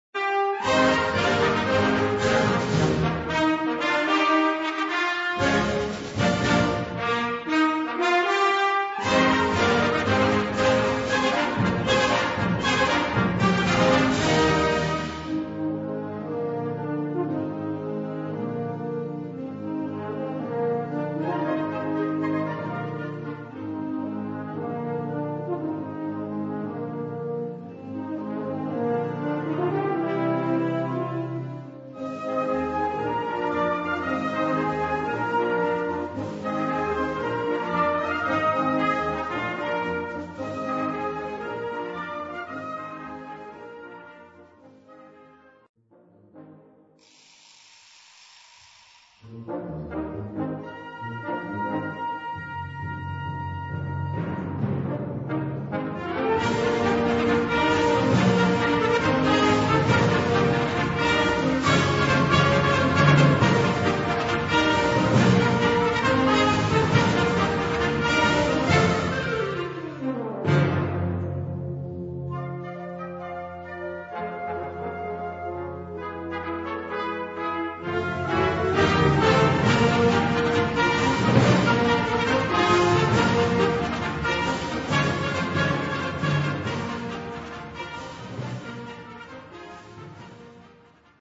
Categoría Banda sinfónica/brass band
Subcategoría Suite
Instrumentación/orquestación Ha (banda de música)